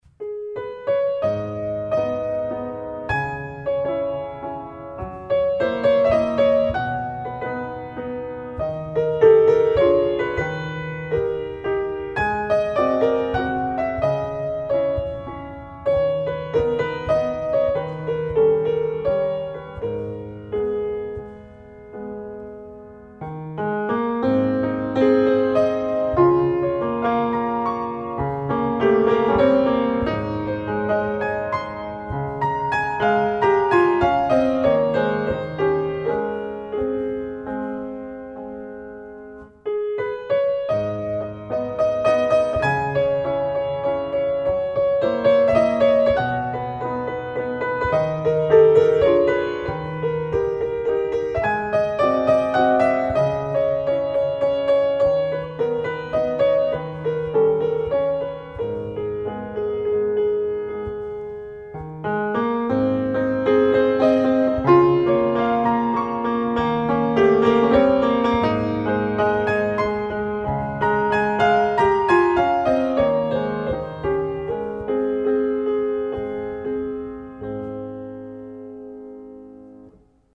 Пьесы для фортепиано (изд. в 2001 г.)